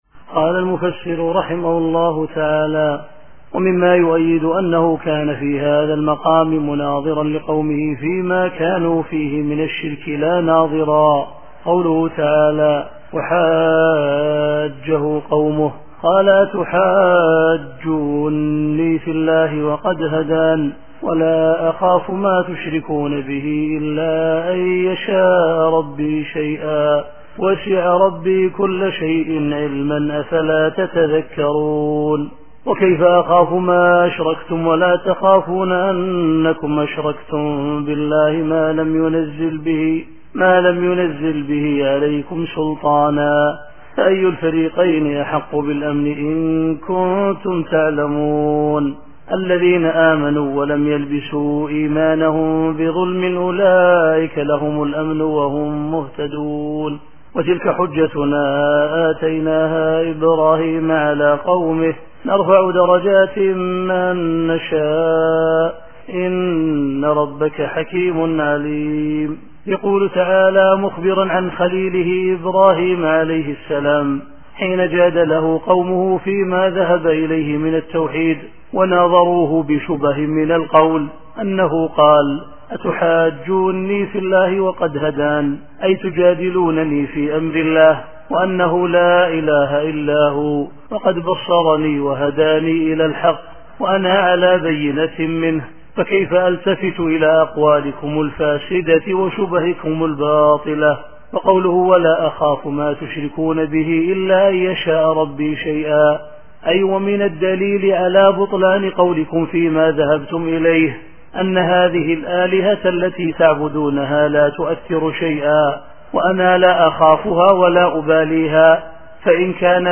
التفسير الصوتي [الأنعام / 80]